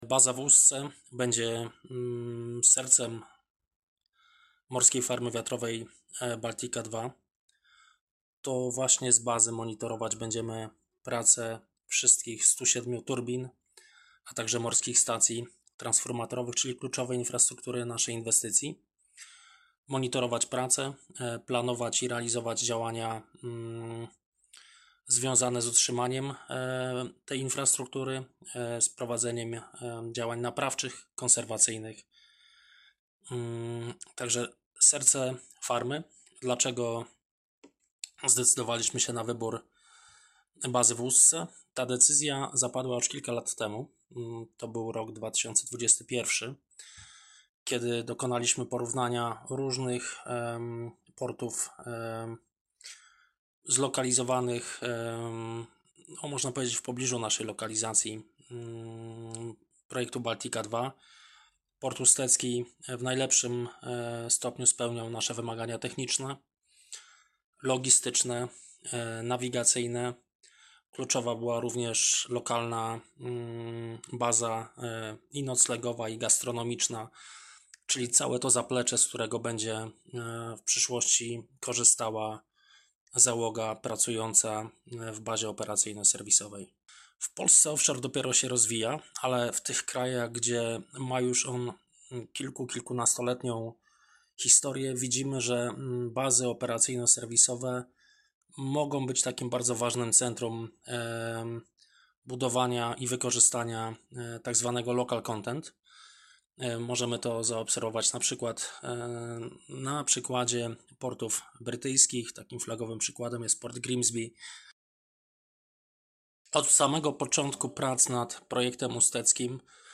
Wypowiedzi audio